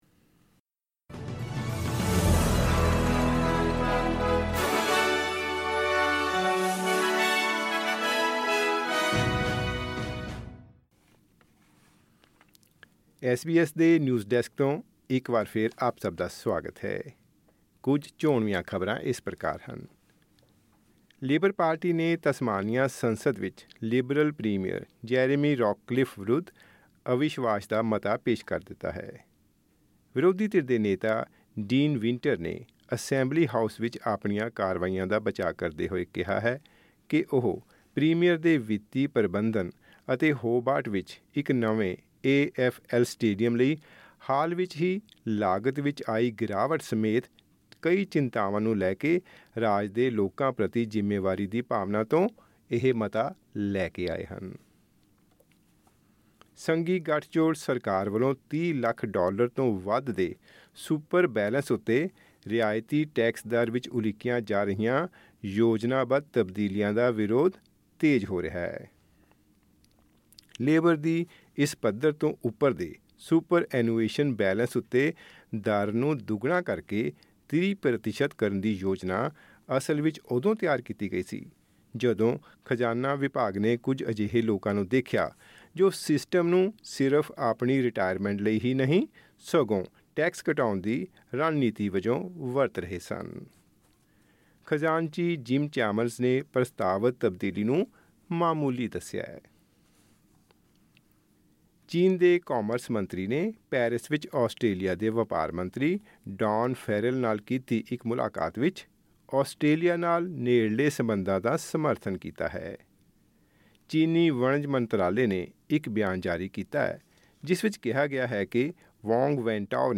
ਖਬਰਨਾਮਾ: ਰਾਇਲ ਚੈਲੰਜਰਸ ਬੈਂਗਲੁਰੂ ਨੇ ਪੰਜਾਬ ਕਿੰਗਜ਼ ਨੂੰ ਹਰਾ ਕੇ ਪਹਿਲੀ ਵਾਰ ਜਿੱਤੀ ਆਈ ਪੀ ਐੱਲ ਟਰਾਫੀ